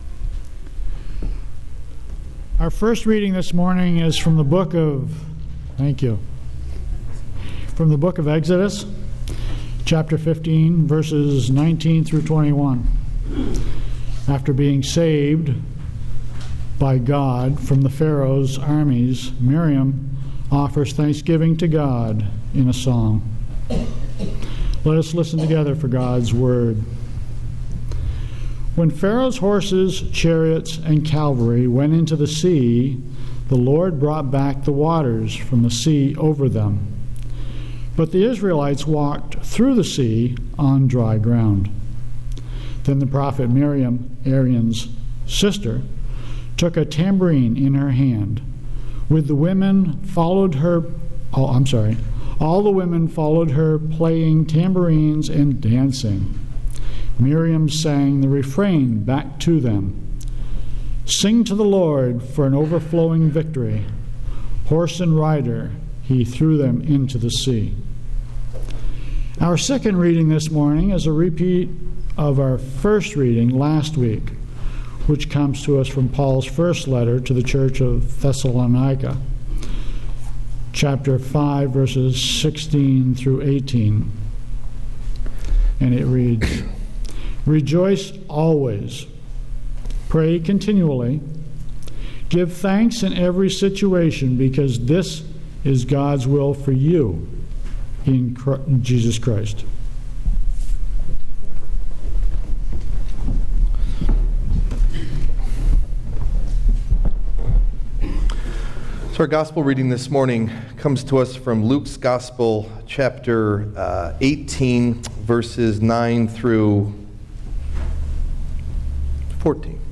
Date: November 23rd, 2014 (Reign of Christ) Message Delivered at: The United Church of Underhill (UCC and UMC) Key Text(s): Exodus 15:19-21; 1 Thessalonians 5:16-18; Luke 18:9-14 This is the second of a two-part sermon series on giving thanks. Today we look at giving thanks in good times.
Message Delivered at: The United Church of Underhill (UCC and UMC)